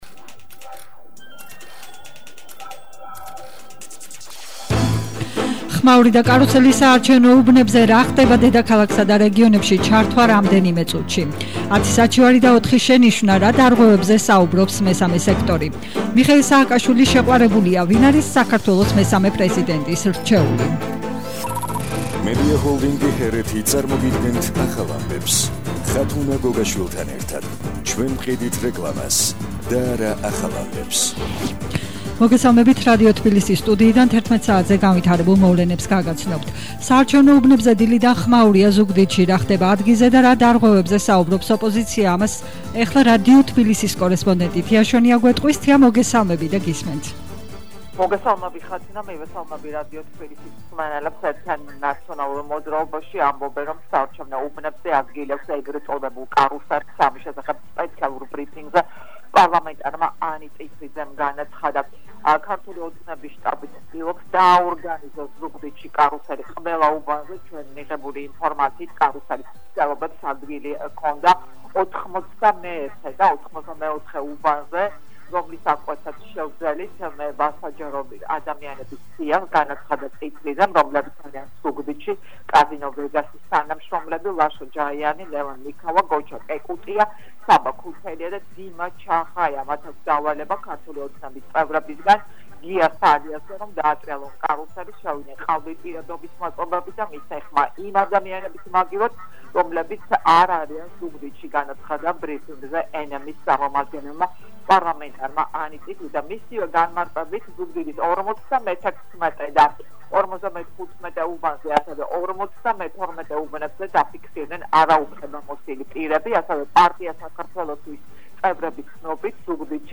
ახალი ამბები 11:00 საათზე –02/10/21 - HeretiFM